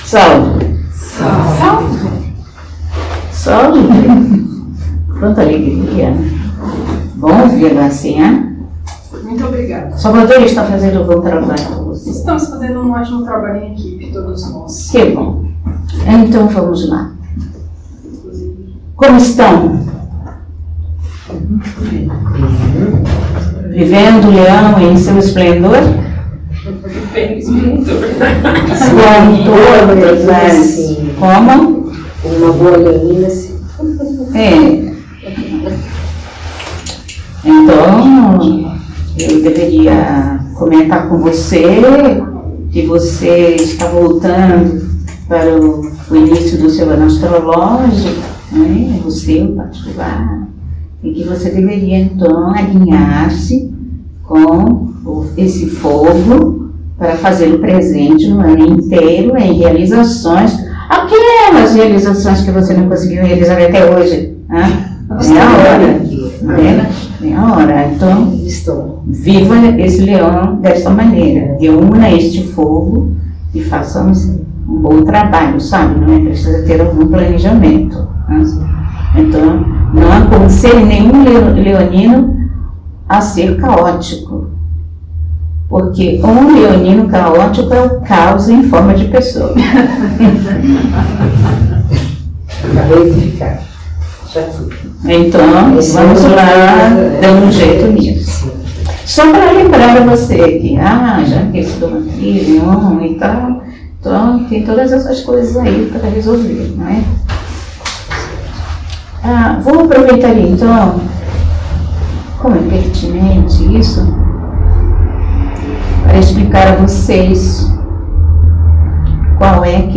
Palestra Canalizada